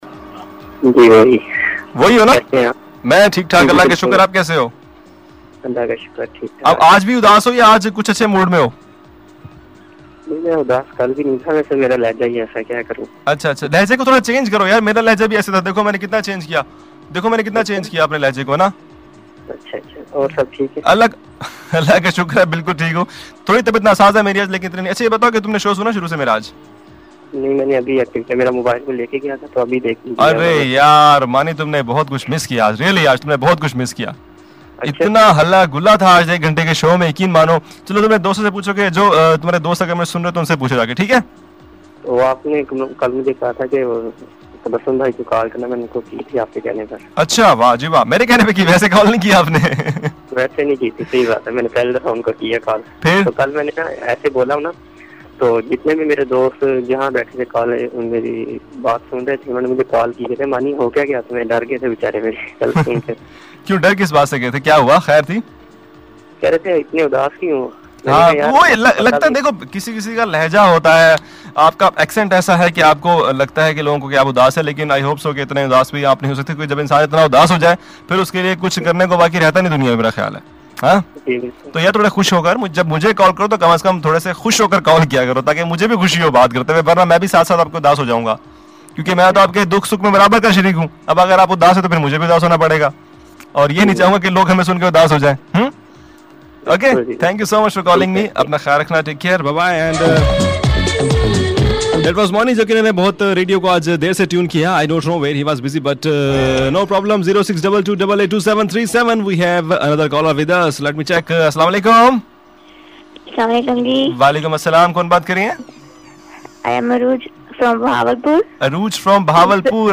Live Call & Celebrity